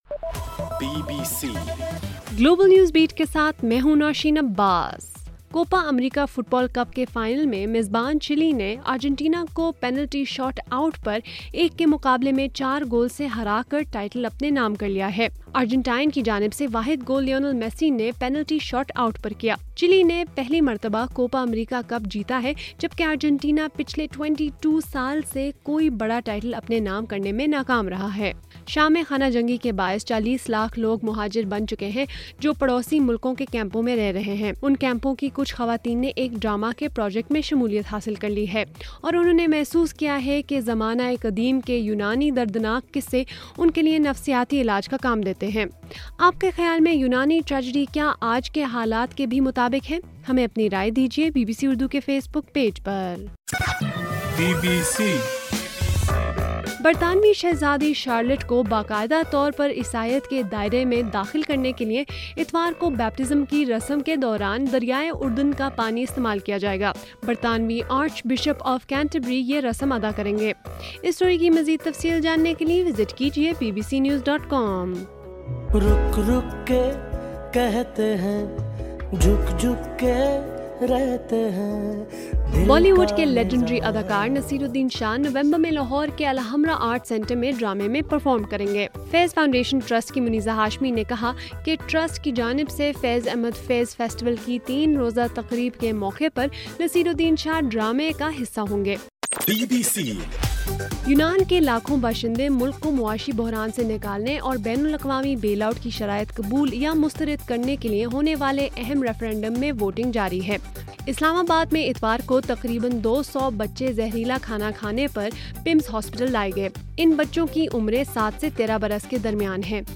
جولائی 5: رات 9 بجے کا گلوبل نیوز بیٹ بُلیٹن